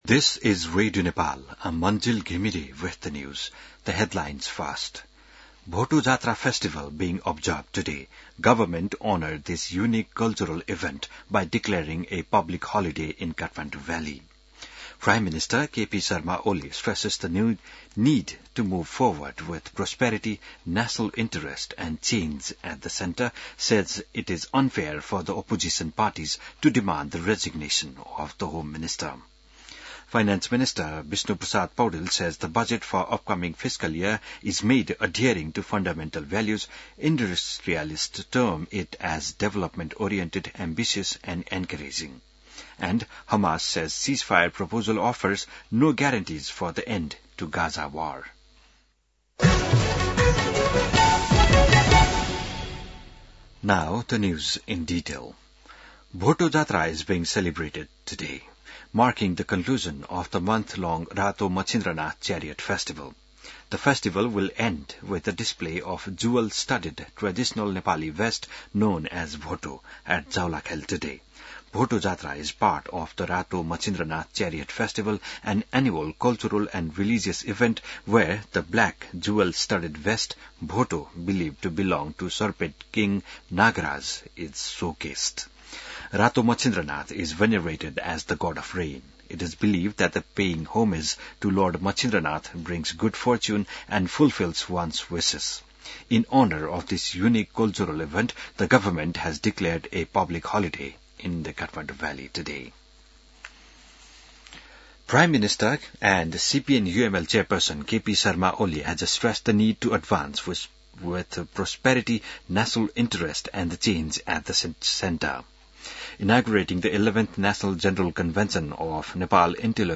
बिहान ८ बजेको अङ्ग्रेजी समाचार : १८ जेठ , २०८२